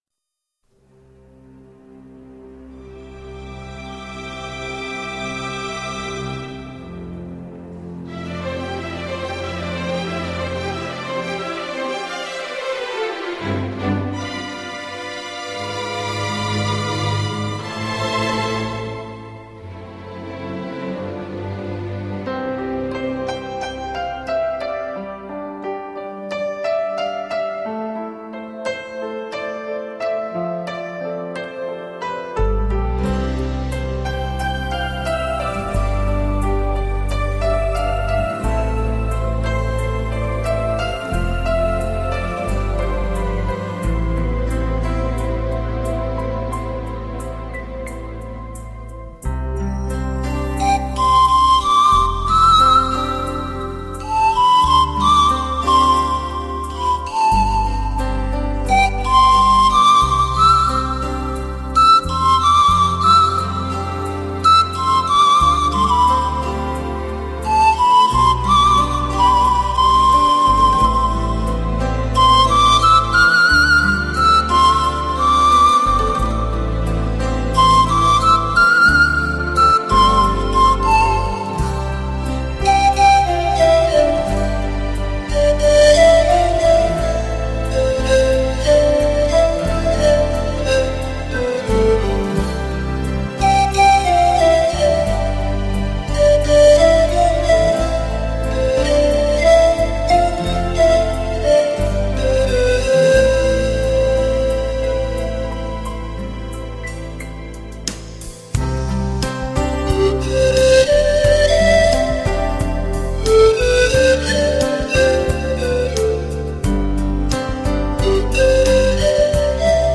中、西餐厅背景音乐精选
沙龙休闲音乐精品之作，权威收录国际知名店播金曲，让你在家也能如此轻松。